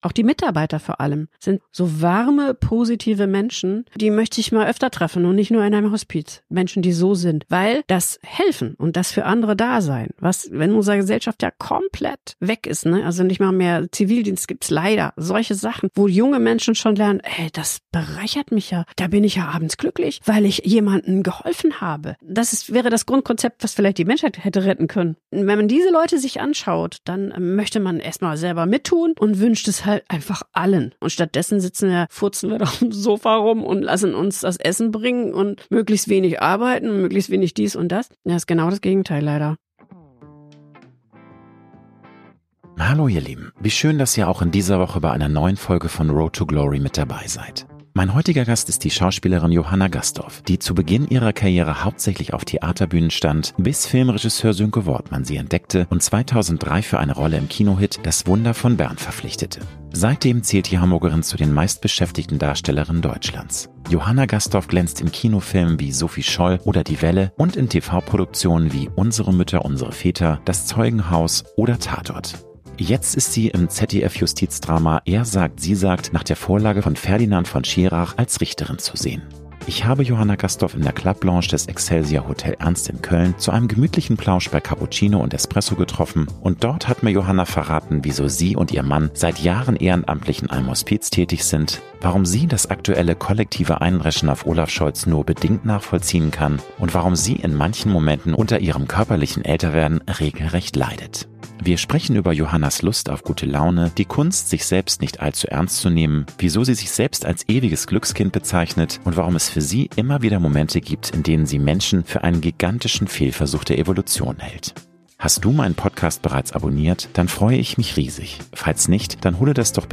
Ich habe Johanna Gastdorf in der Club-Lounge des Excelsior Hotel Ernst in Köln zu einem gemütlichen Plausch bei Cappuccino und Espresso getroffen und dort hat mir Johanna verraten, wieso sie und ihr Mann seit Jahren ehrenamtlich in einem Hospiz tätig sind, warum sie das aktuelle kollektive Eindreschen auf Olaf Scholz nur bedingt nachvollziehen kann und warum sie in manchen Momenten unter ihrem körperlichen Älter werden regelrecht leidet. Wir sprechen über Ihre Lust auf gute Laune, die Kunst, sich selbst nicht allzu ernst zu nehmen, warum sie sich selbst als ewiges Glückskind bezeichnet und es für sie immer wieder Momente gibt, in denen sie Menschen für einen gigantischen Fehlversuch der Evolution hält.